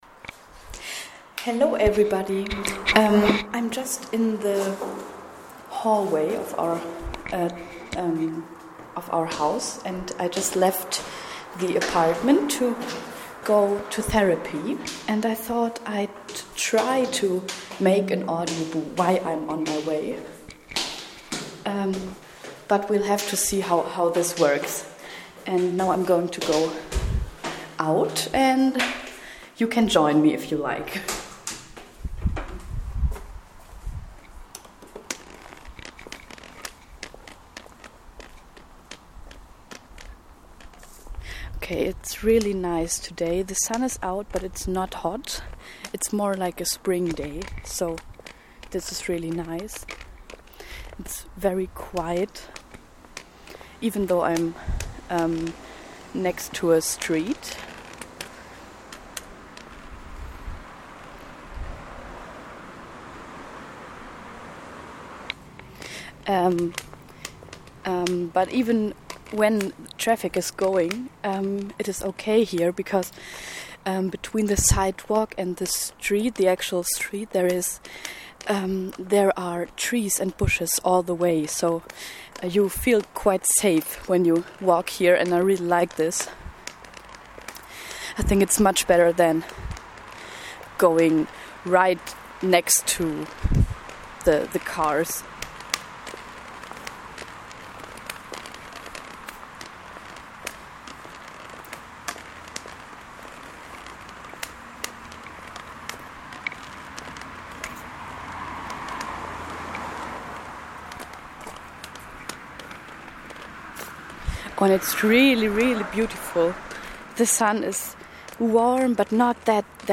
Join me while I'm walking and rambling about the way :-)